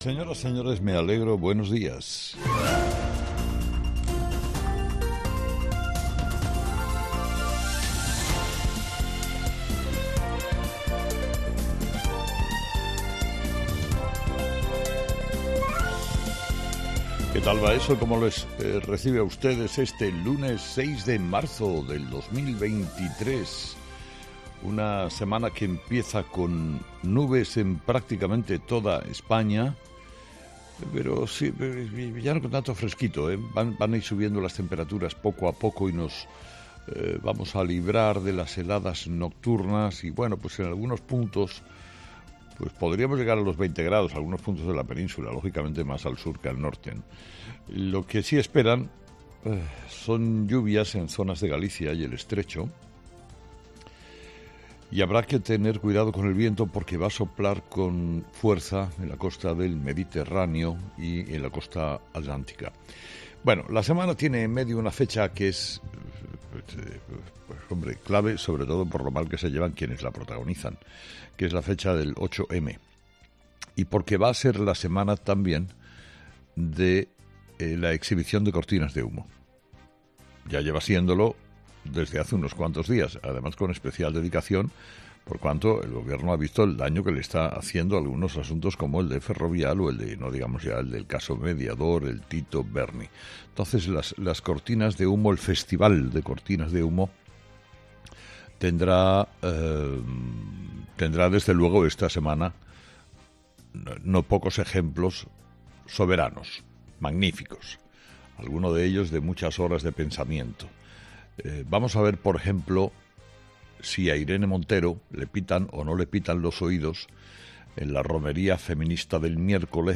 Carlos Herrera, director y presentador de 'Herrera en COPE', comienza el programa de este lunes analizando las principales claves de la jornada, que pasan, entre otros asuntos, por la reforma de la ley del 'solo sí es sí' que llegará al Congreso en la víspera del día de la mujer.